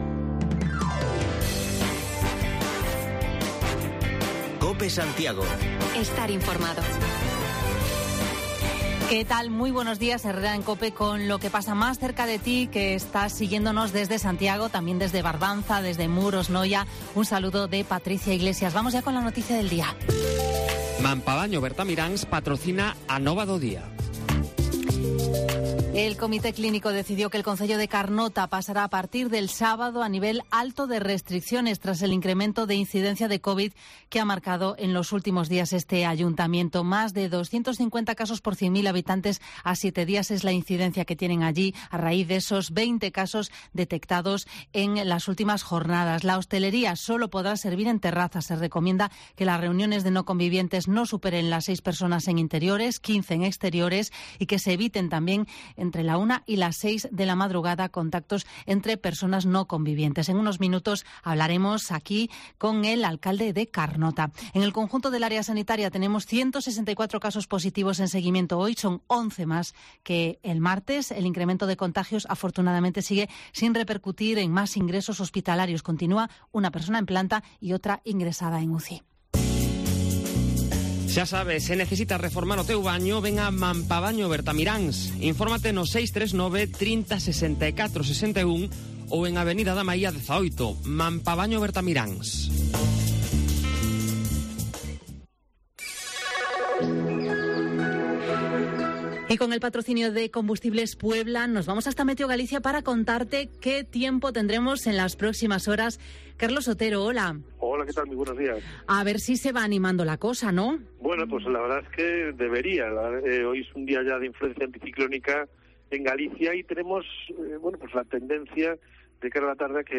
Actualizamos lo más destacado de la jornada y nos acercamos hasta Carnota, que desde el sábado entra en nivel alto de restricciones por el incremento de casos de de covid. Hablamos con su alcalde, Juan Manuel Saborido